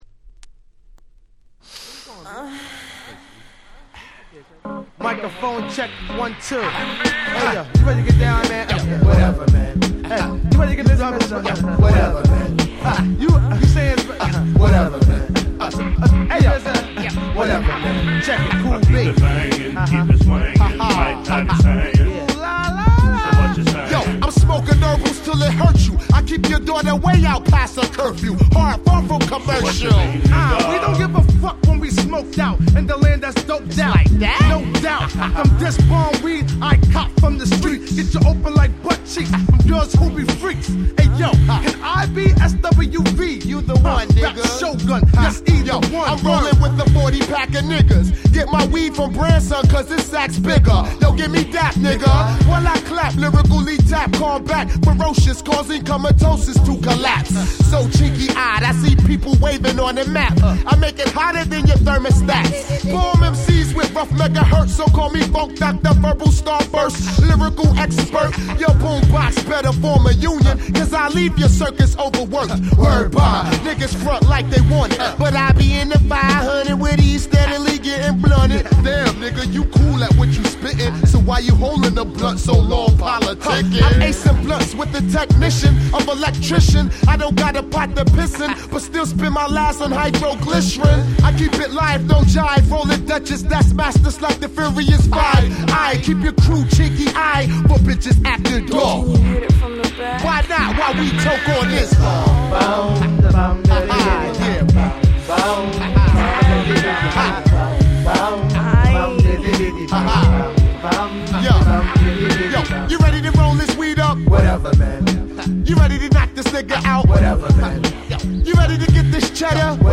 97' Smash Hit Hip Hop !!
Boom Bap